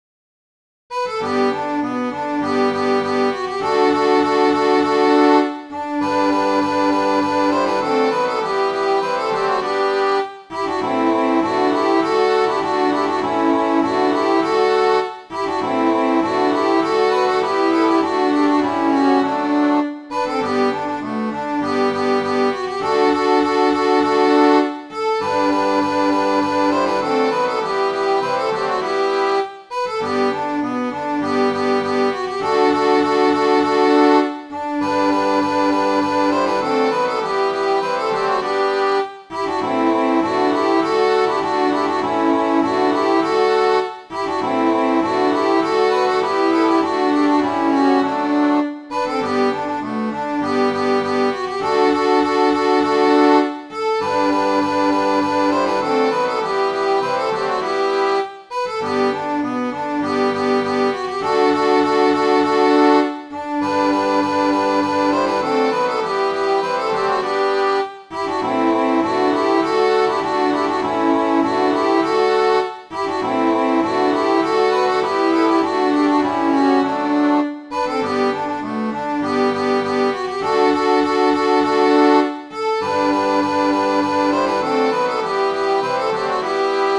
Midi File, Lyrics and Information to A Wet Sheet and A Flowing Sea